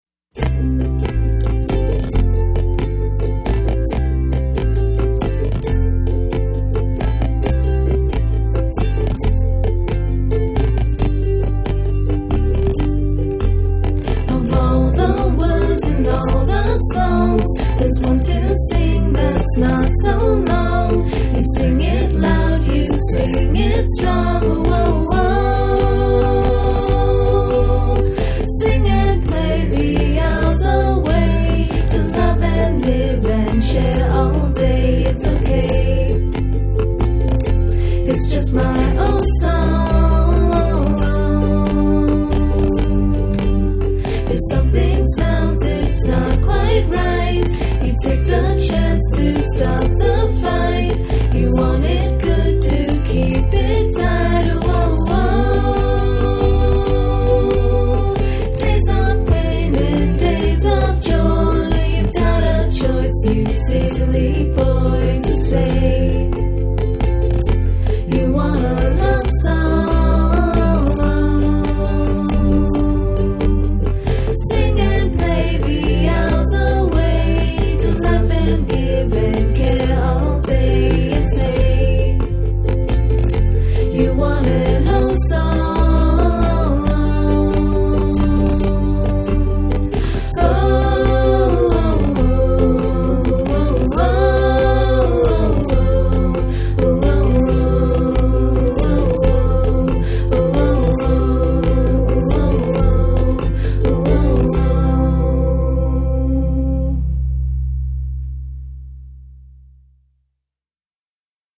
This acoustic harmonizing duo